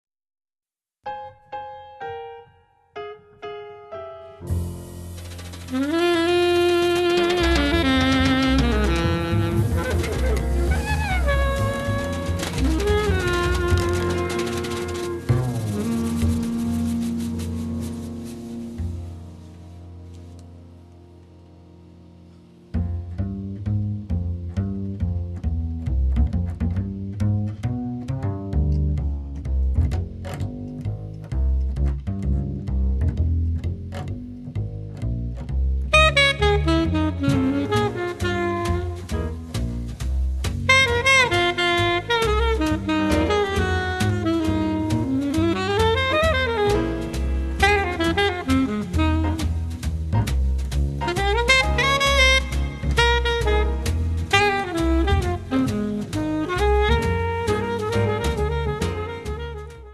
piano
saxophone
trumpet
vocals
bass
percussions
drums